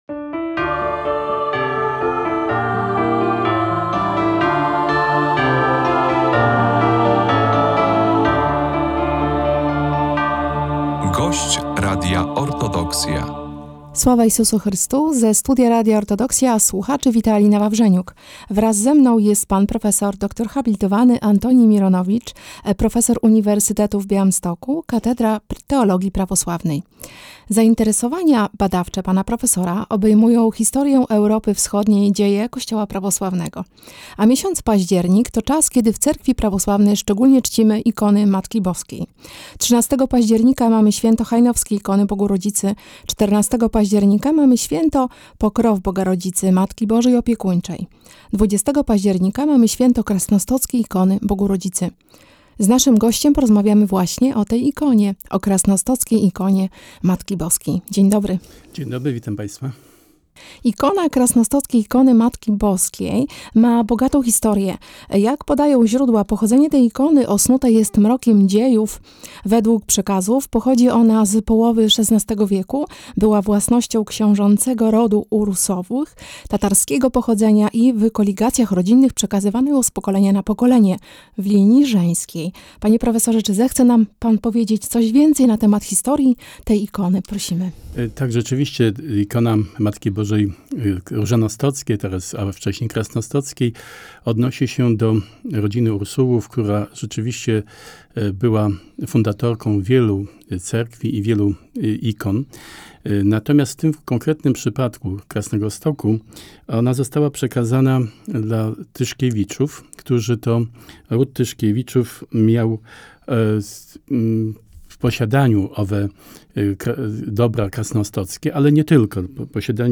Żeński Monaster w Zwierkach jest kontynuatorem istniejącego na początku XX wieku monasteru w Krasnymstoku. Znajdowała się tam cudowna ikona Matki Bożej. Zapraszamy do wysłuchania rozmowy